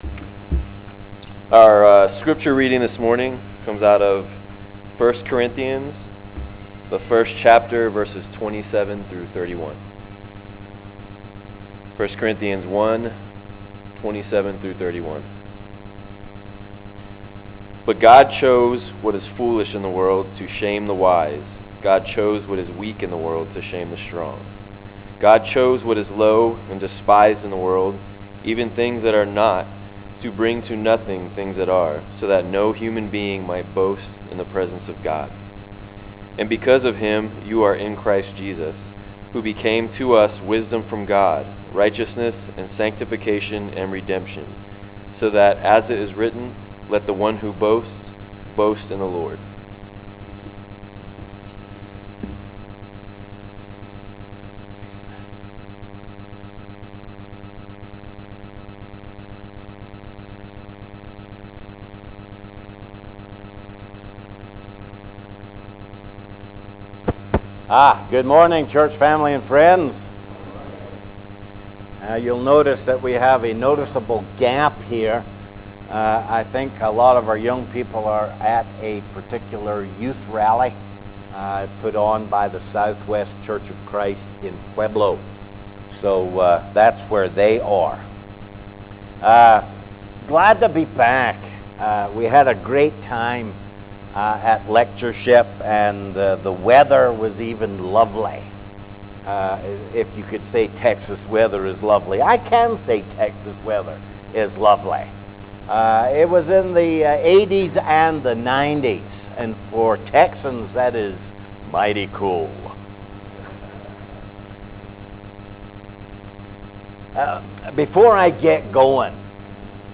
9-25-11 sermon am